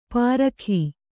♪ pārakhī